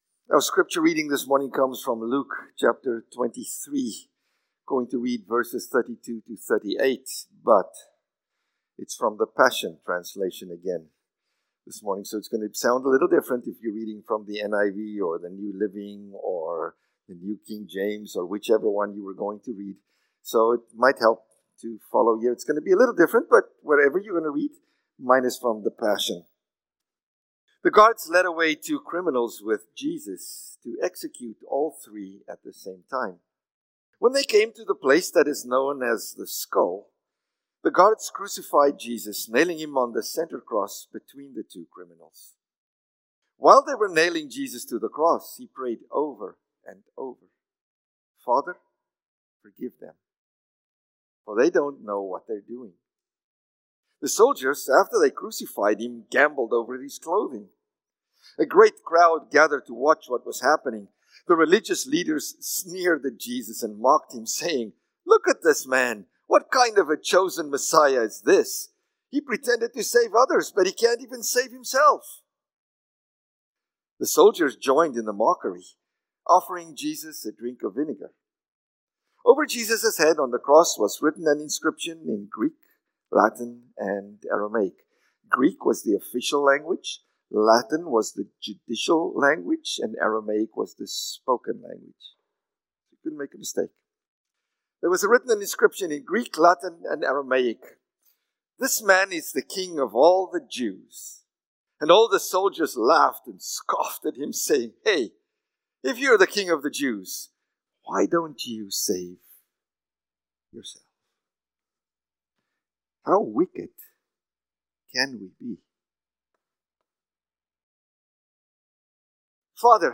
Good-Friday-Sermon.mp3